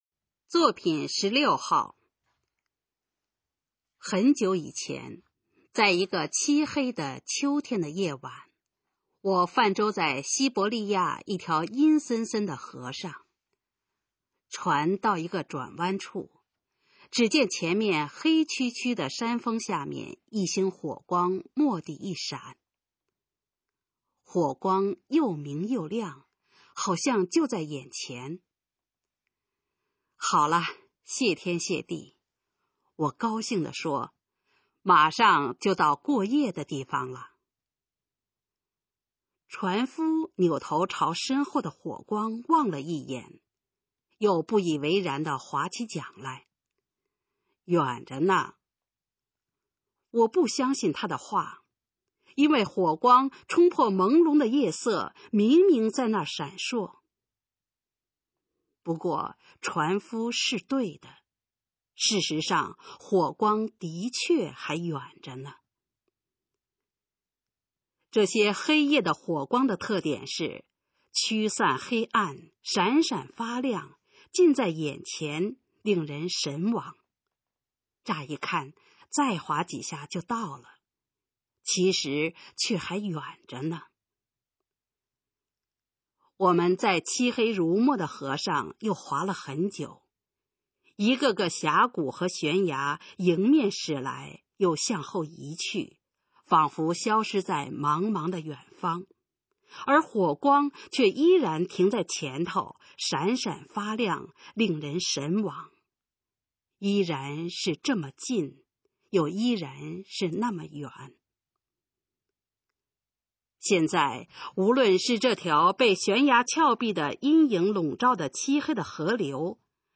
《火光》示范朗读_水平测试（等级考试）用60篇朗读作品范读